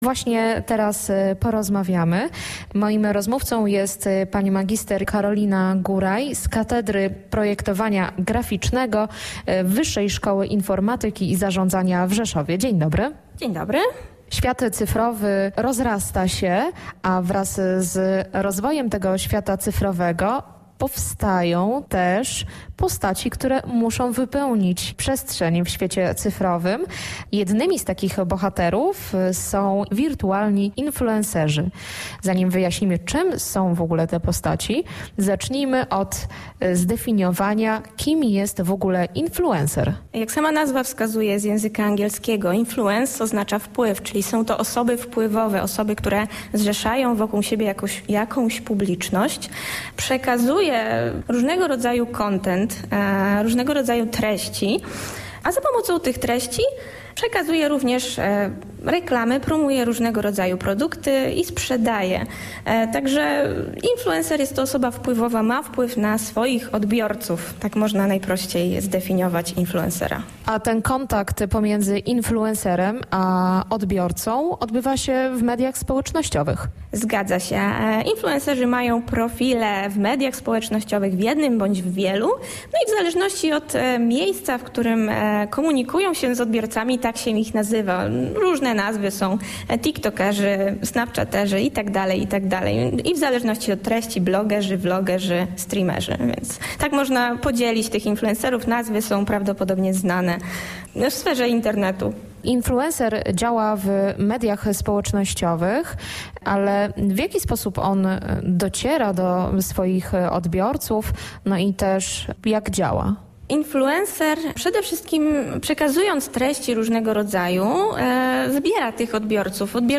Gwałtowny rozwój nowych mediów doprowadził do powstania tzw. wirtualnych influencerów. Kim oni są i w jaki sposób tworzy się takie postacie? Czy wirtualne awatary zastąpią niebawem prawdziwych ludzi? Rozmowa